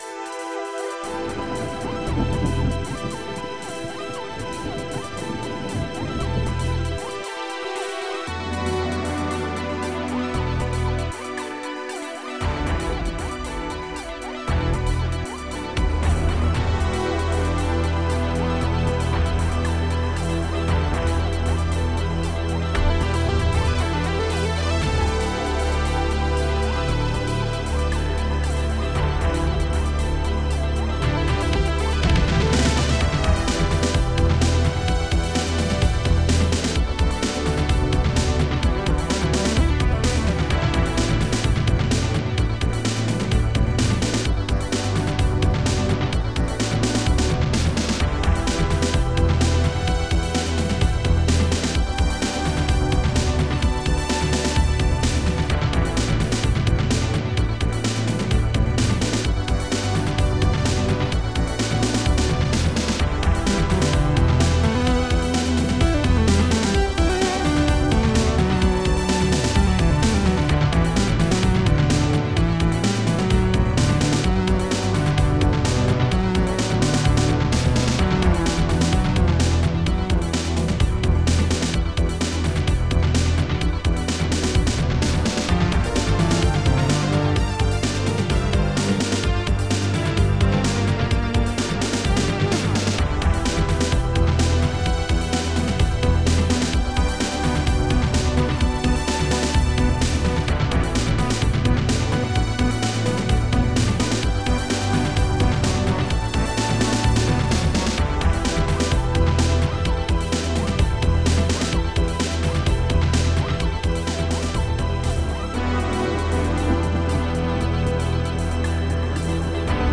mod (ProTracker MOD (8CHN))
Etherstring
Thunder
Hihat closed
Hihat opened
Bass
Lowstring
Bassdrum
Snare
Biggong
Wood block echo
Bass saw